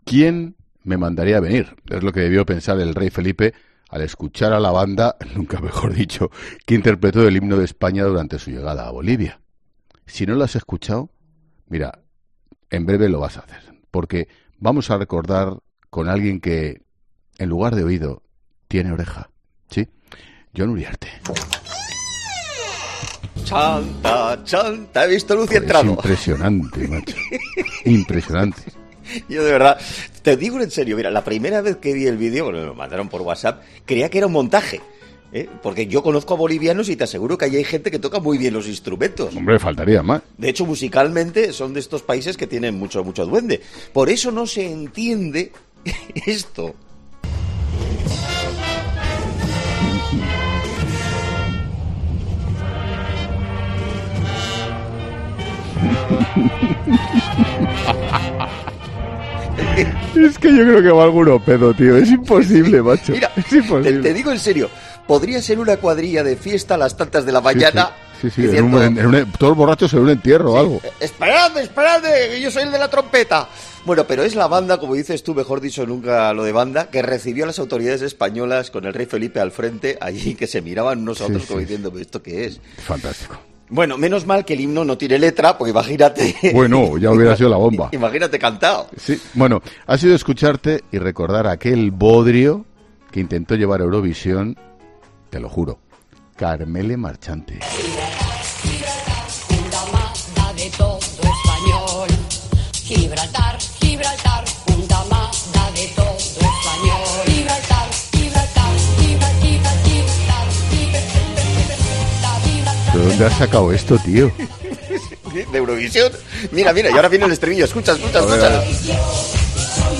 Ha sido una de los sonidos del fin de semana, el himno de España en Bolivia, en 'La Linterna' te damos unos consejos para no hacer pasarlo mal a quienes te estén escuchando